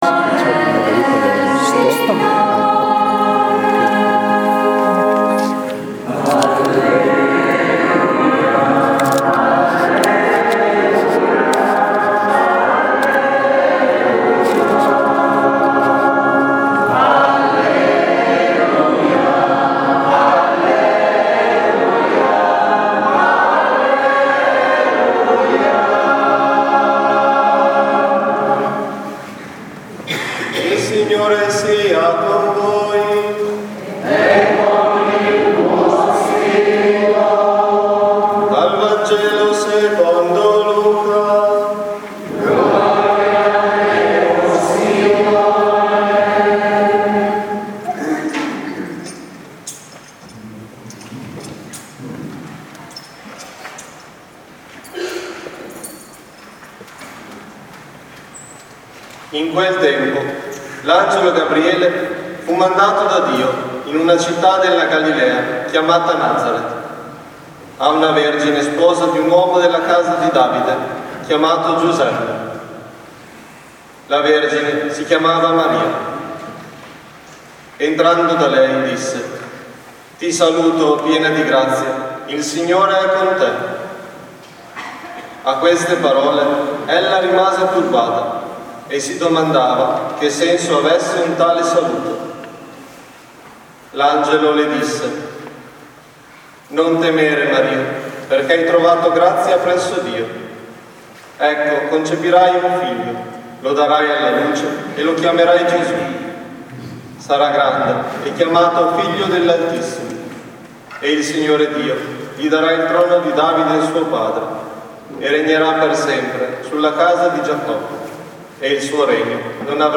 Carissimi, GRAZIE per aver festeggiato con noi il giorno dell’Annuncio del Signore.
Ora potete ascoltare l’audio dell’omelia del nostro Vescovo Mons Edoardo Cerrato.
omelia-mons-cerrato-annunciazione.mp3